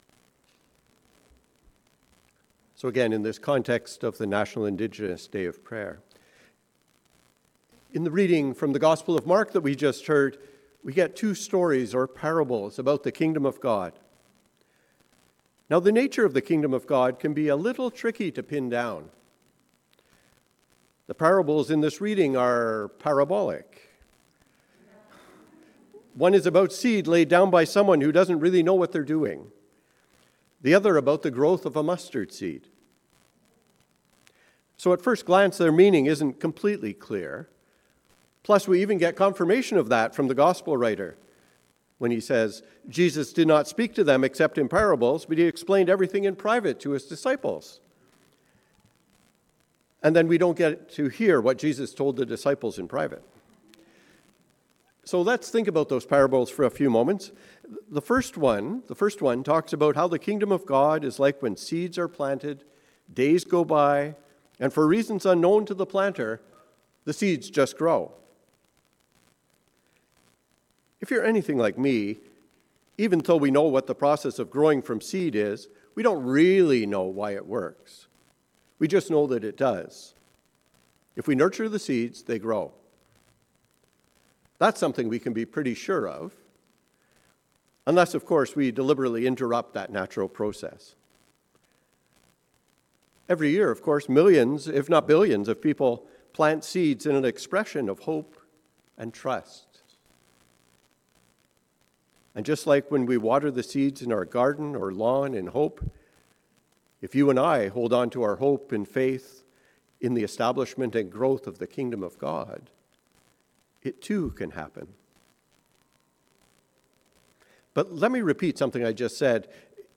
Sermon for the Fourth Sunday After Pentecost